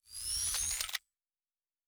Weapon 16 Reload 3 (Laser).wav